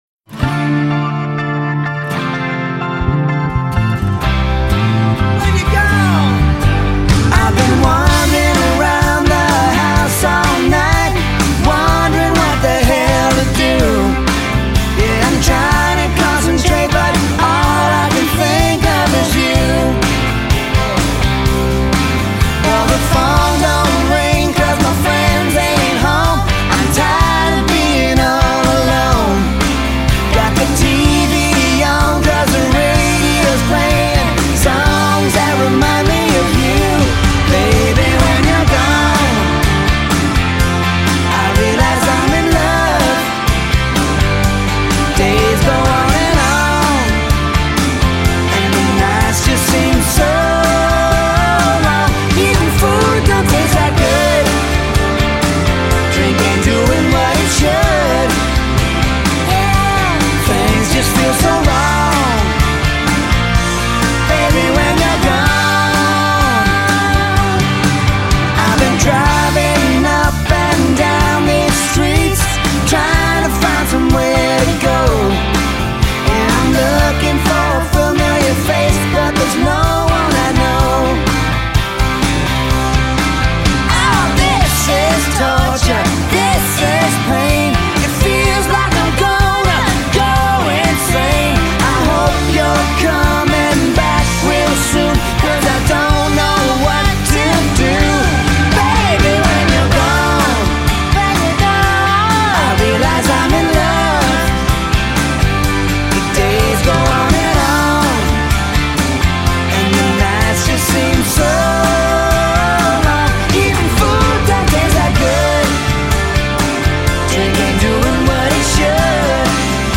Genre: Rock/Pop